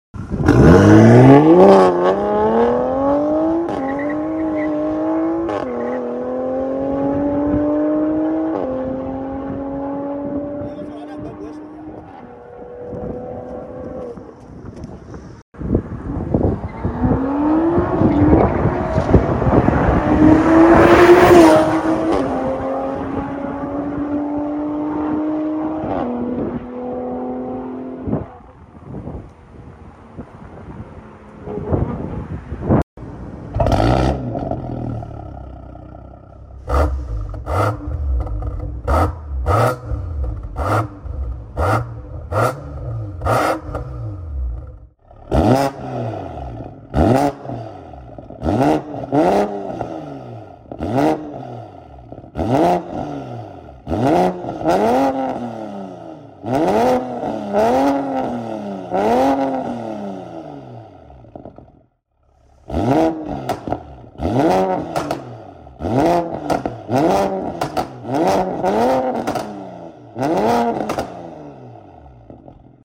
500HP BMW G30 LCI 540i Sound Effects Free Download